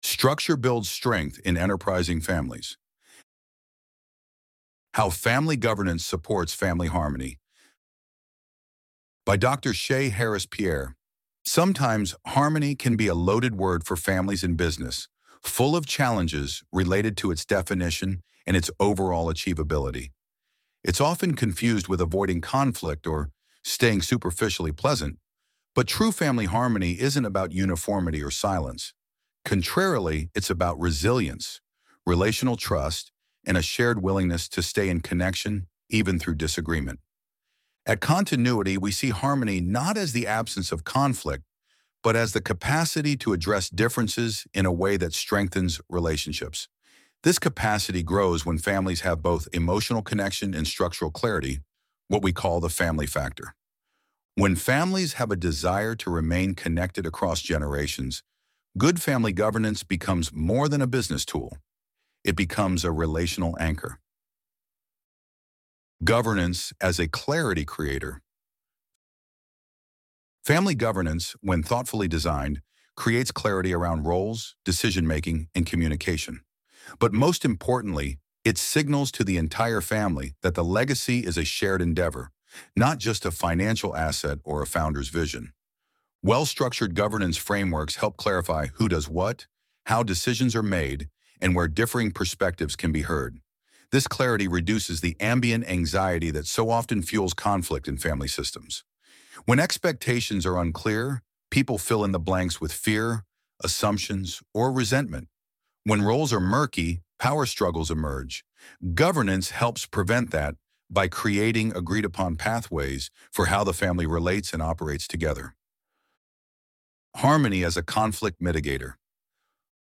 Structure Builds Strength in Enterprising Families How Family Governance Supports Family Harmony Loading the Elevenlabs Text to Speech AudioNative Player...